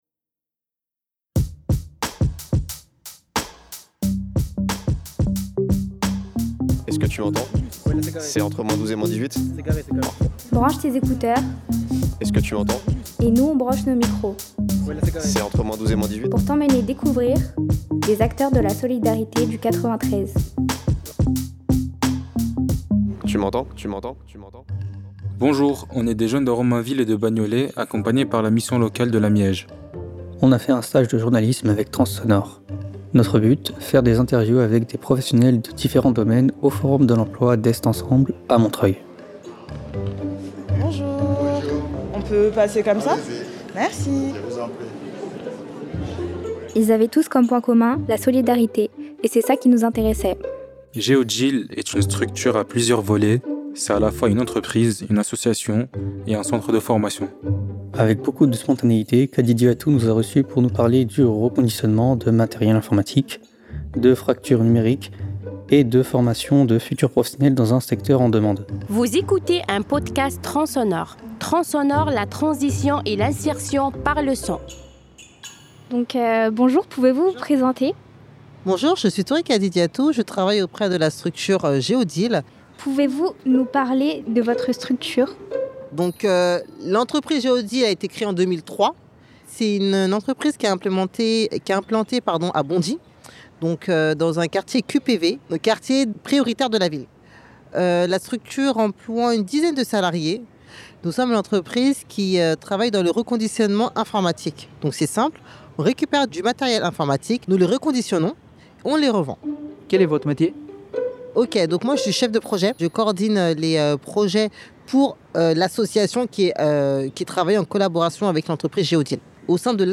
Nous sommes des jeunes de Romainville et Bagnolet et voici le dernier épisode que nous avons réalisé accompagné·es par la mission locale de la MIEJ.